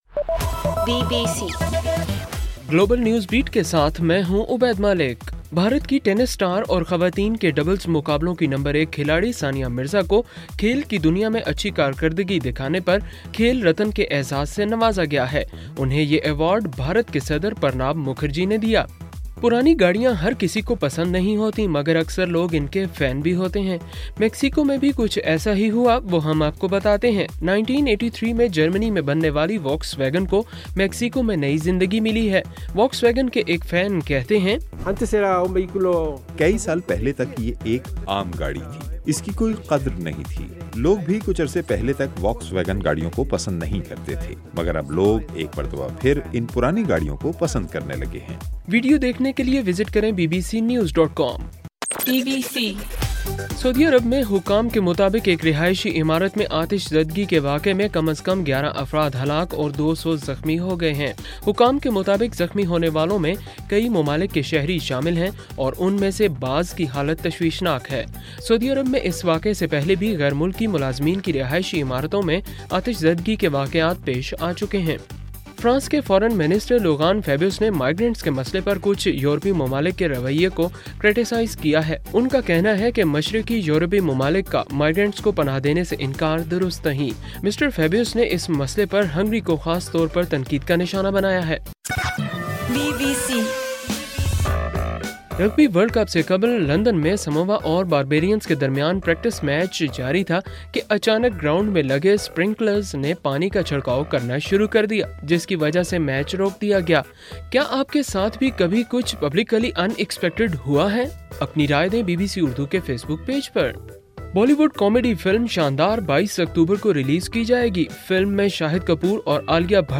اگست 30: رات 9 بجے کا گلوبل نیوز بیٹ بُلیٹن